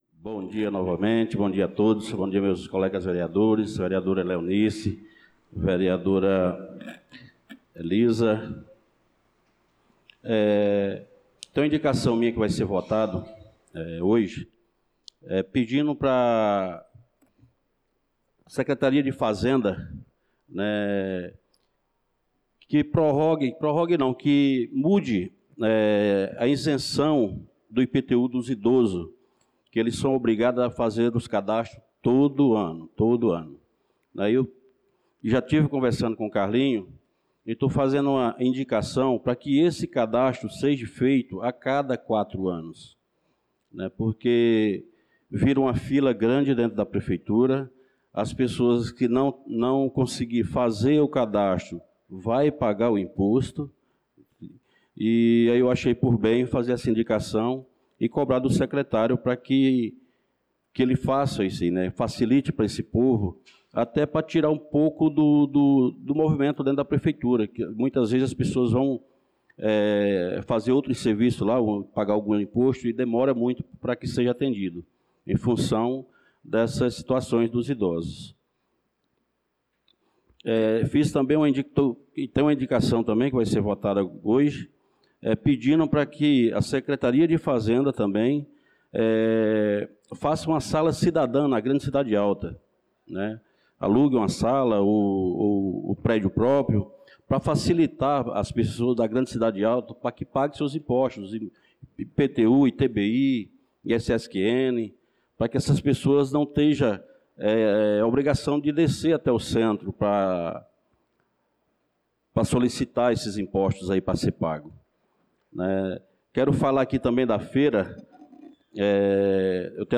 Pronunciamento do vereador Francisco Ailton na Sessão Ordinária do dia 28/04/2025